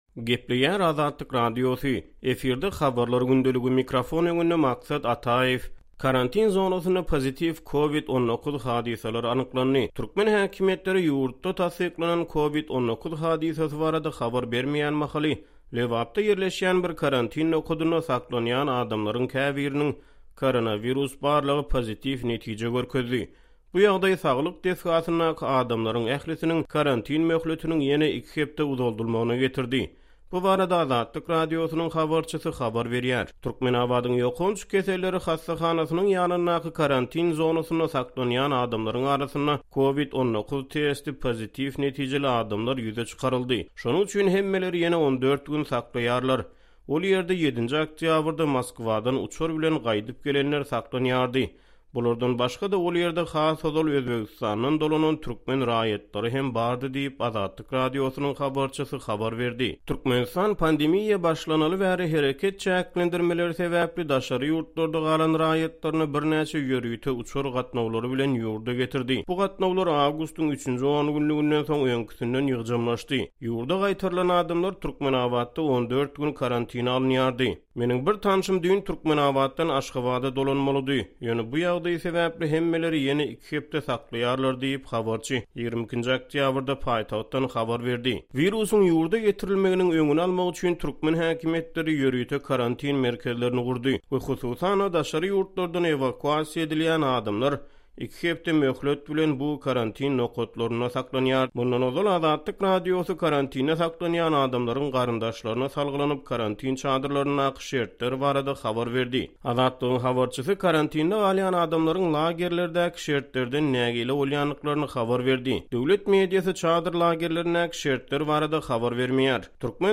Bu ýagdaý saglyk desgasyndaky adamlaryň ählisiniň karantin möhletiniň ýene iki hepde uzaldylmagyna getirdi. Bu barada Azatlyk Radiosynyň habarçysy habar berýär.